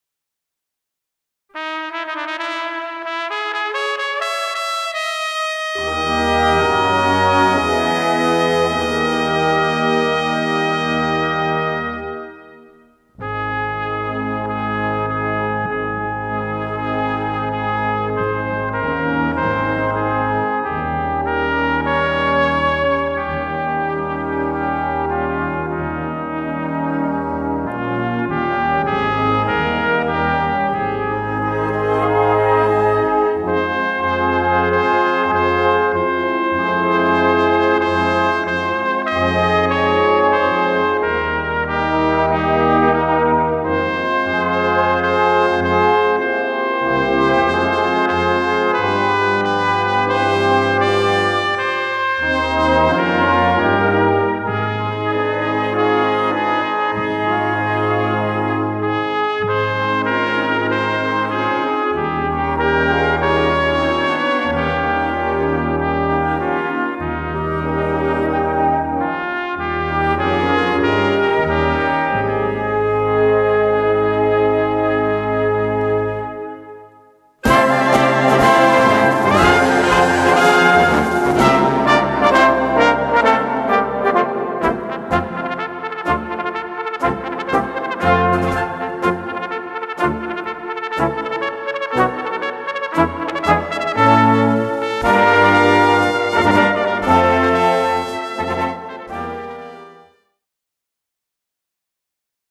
Solo für 1 Trompete und Blasorchester
Besetzung: Blasorchester